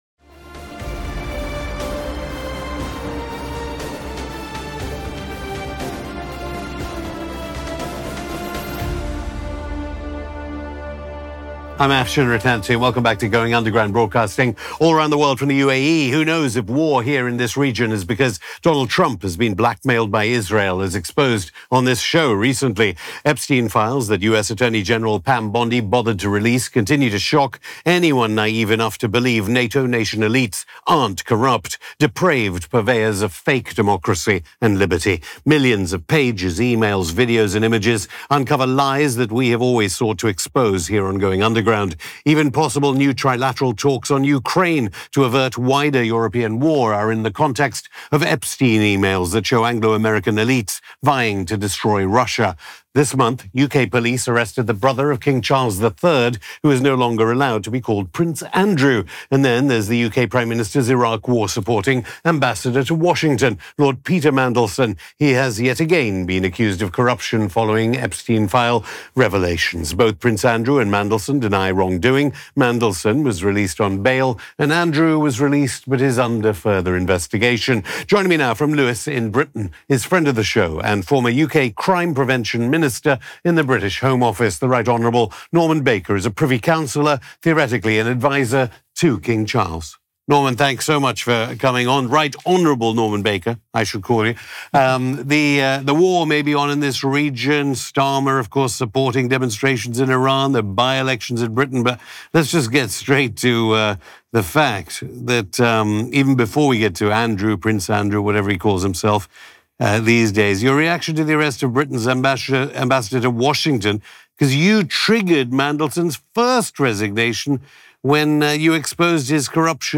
On this episode of Going Underground, we speak to Rt. Hon. Norman Baker, former U.K. Home Office Minister for Crime Prevention and Privy Councillor to King Charles III.